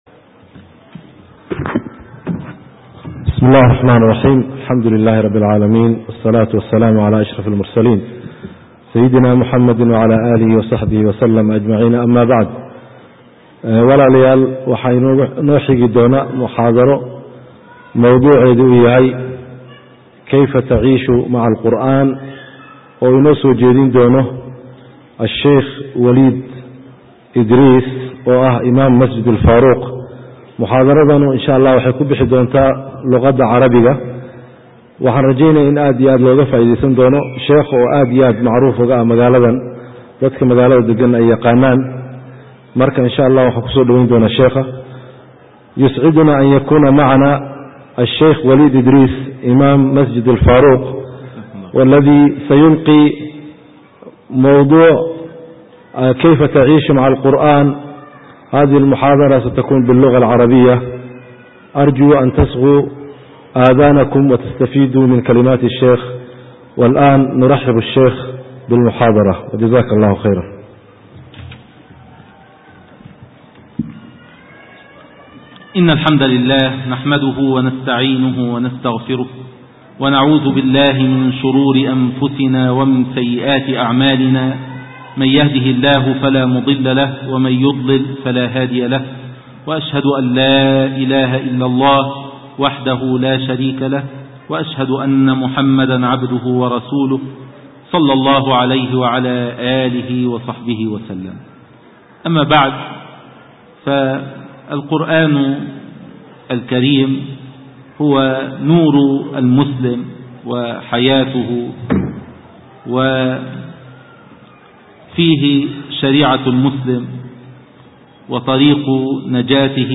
Muxaadaro, Fadliga Qur'aanka